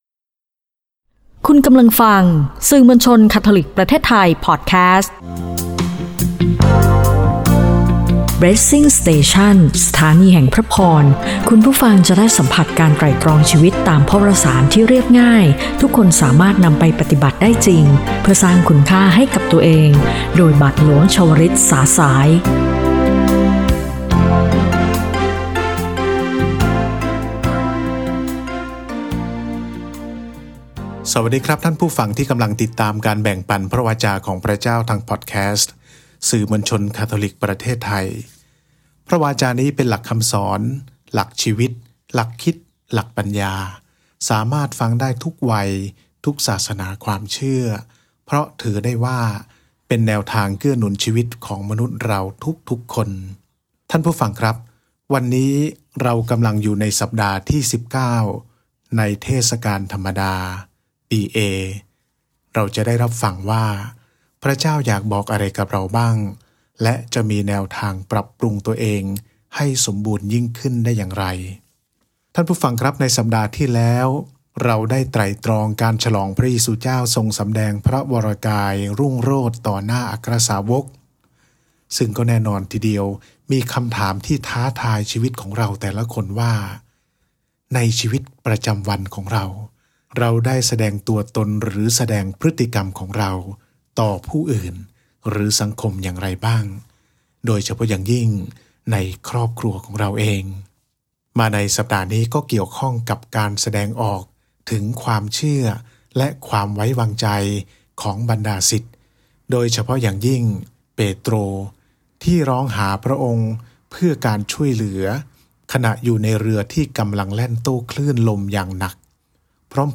BLESSING STATION “สถานีแห่งพระพร” | “พระเจ้าข้า ช่วยข้าพเจ้าด้วย” | EP 31 - รายการวิทยุคาทอลิก